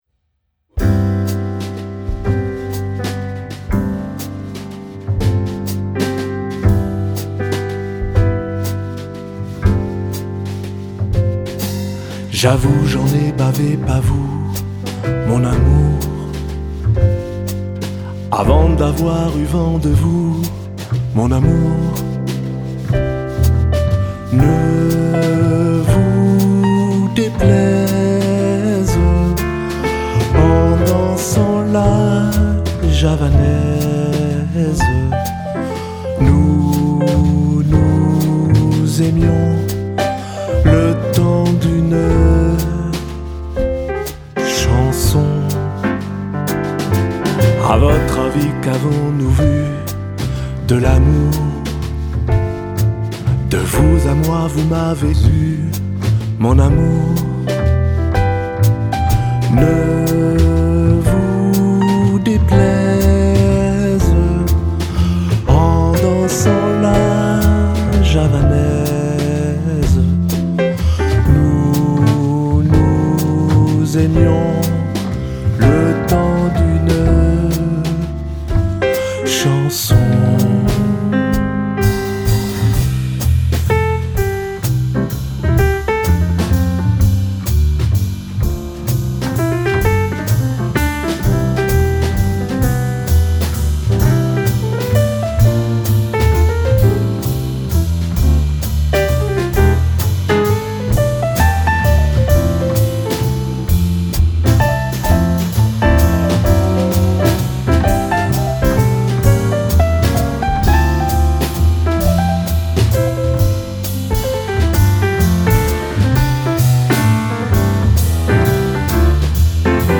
Französische Chansons & stilvoller Jazz
Piano & Gesang
Schlagzeug
Kontrabass